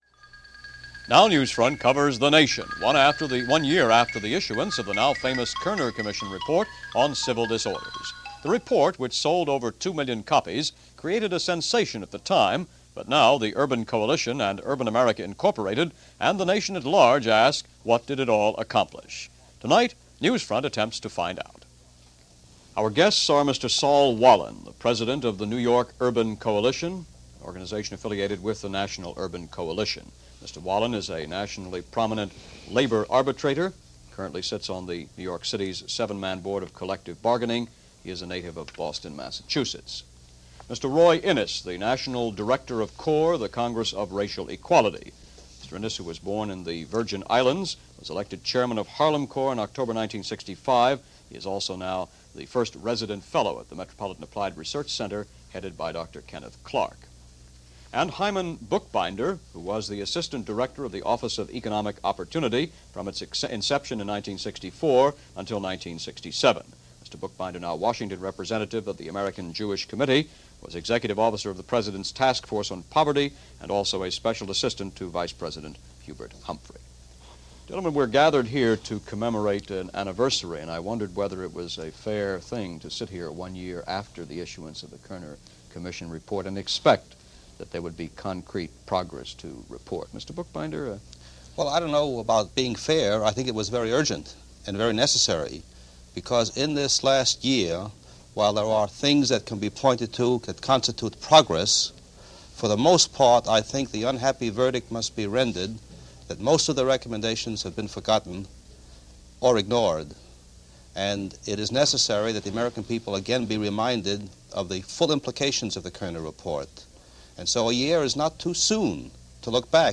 Discussion on the landmark report and how it has gone, one year later - in 1969.